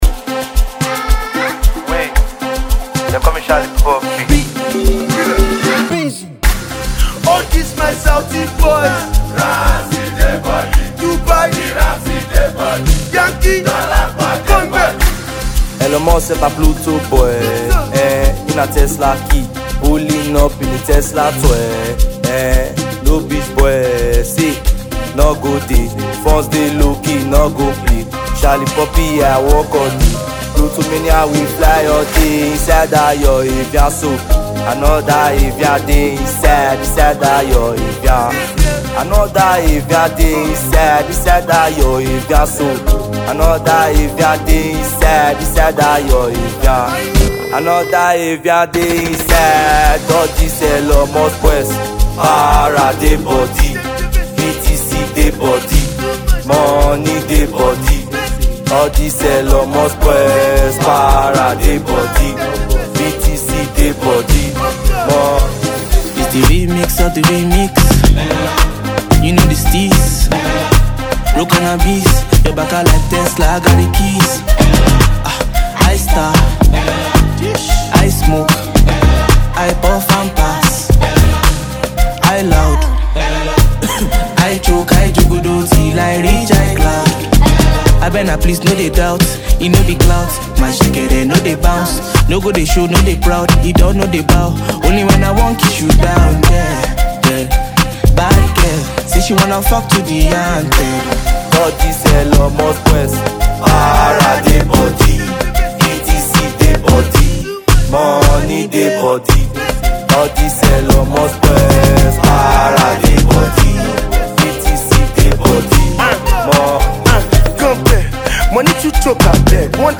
Najia Afrobeat